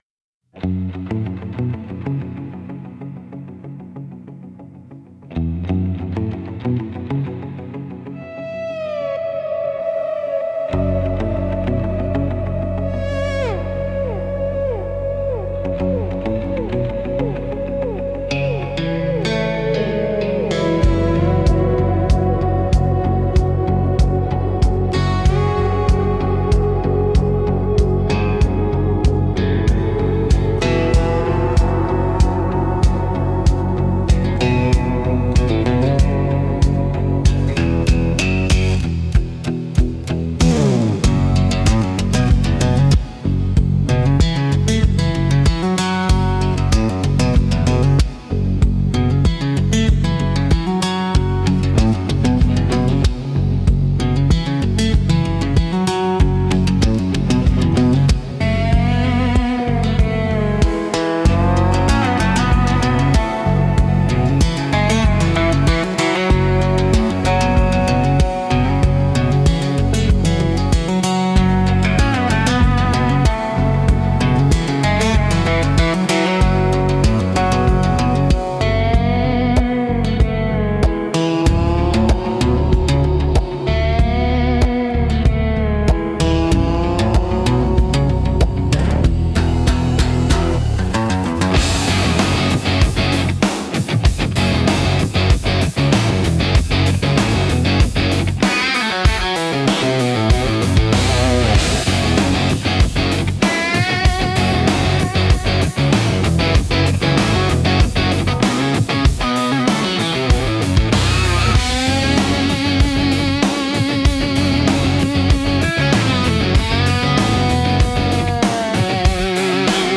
Instrumental - Six Strings at Sundown 4.51